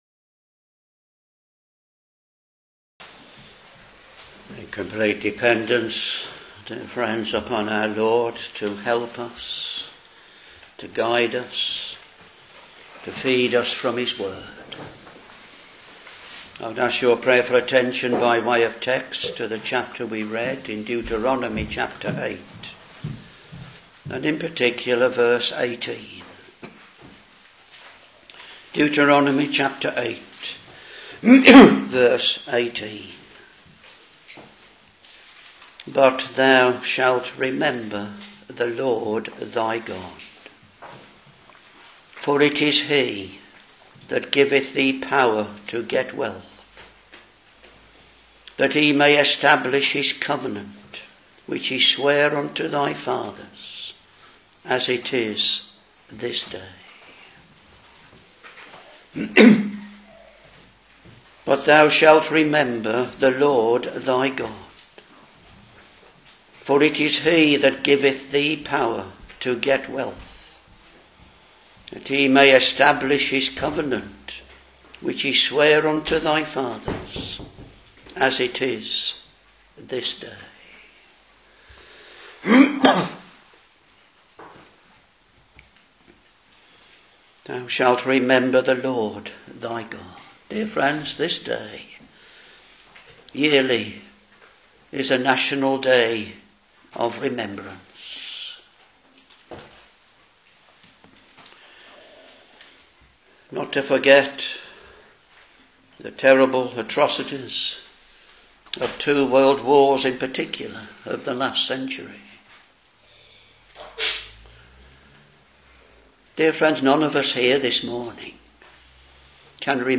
Back to Sermons Deuteronomy Ch.8 v.18